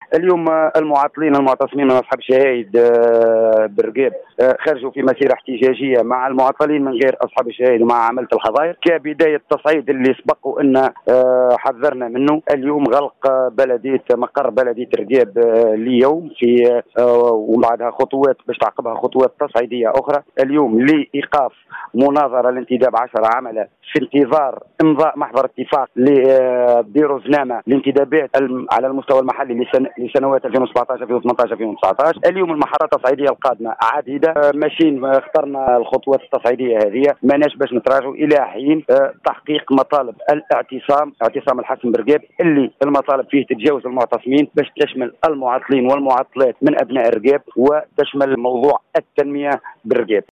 Ecoutez la déclaration d’un protestataire au micro du correspondant de Jawhara Fm.